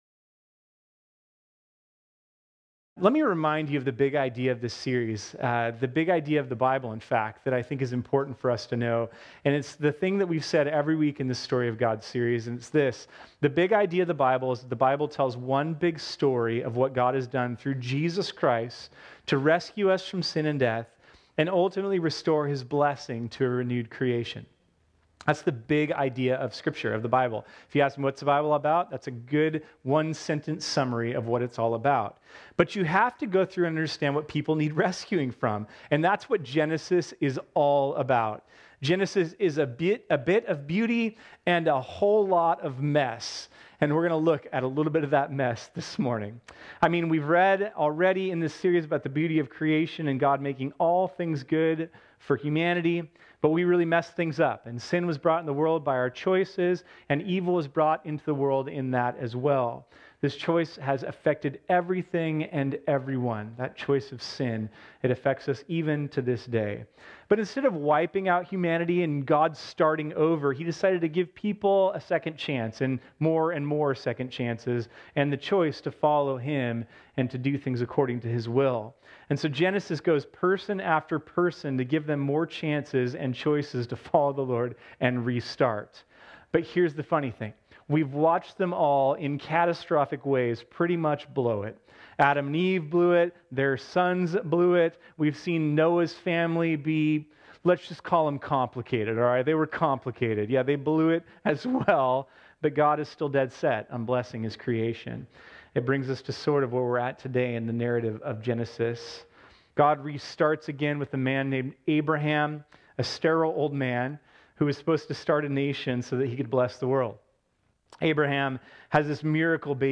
This sermon was originally preached on Sunday, May 5, 2019.